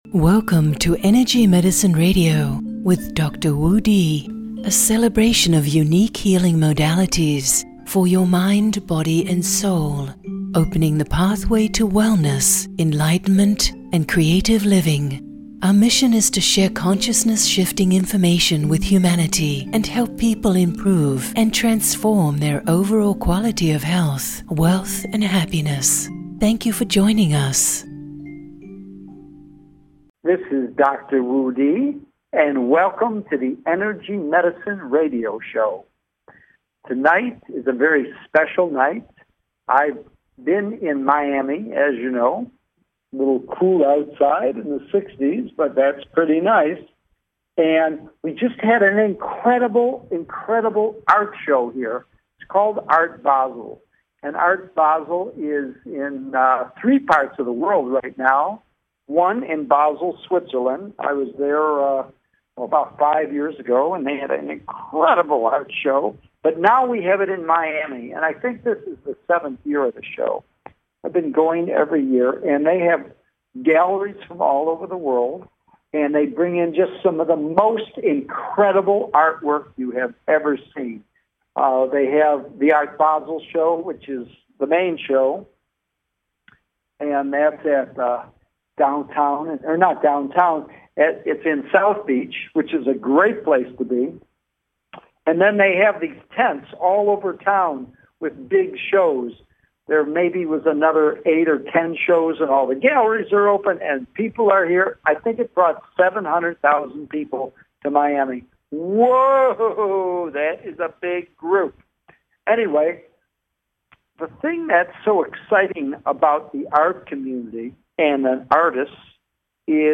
Live on Air: Every 1st and 3rd Tuesday 8-9pm EST (5-6pm PST) USA Join me as I support expansion into your full potential while paving the way to profound transformation and healing.
Featuring Special Live Interviews with healers, spiritual teachers, visionary artists, authors, musicians, and other inspirational individuals.